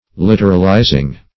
Literalizing - definition of Literalizing - synonyms, pronunciation, spelling from Free Dictionary
literalizing.mp3